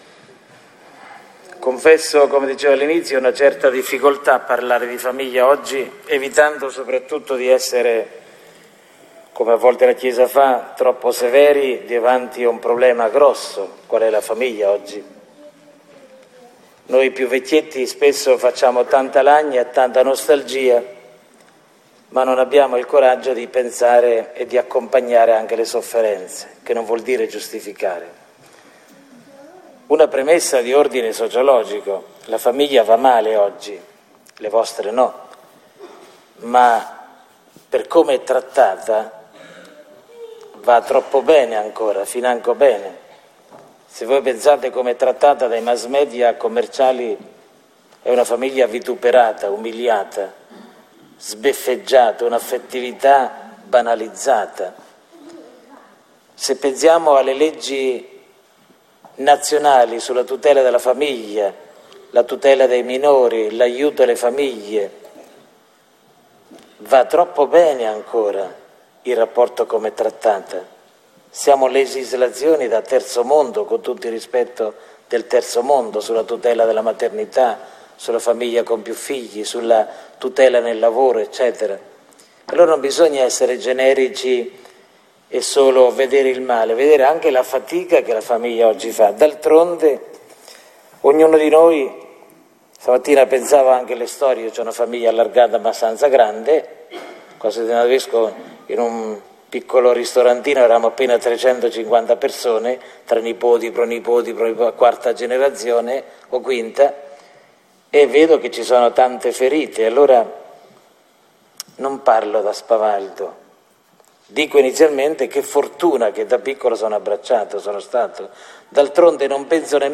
Il vescovo Armando nella messa della festa della Santa Famiglia.